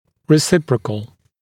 [rɪ’sɪprəkl][ри’сипрэкл]взаимный, обоюдный